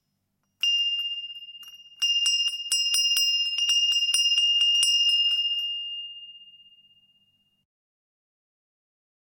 Звуки дверного колокольчика
Звук домашнего дверного колокольчика: нежный звон от прикосновения руки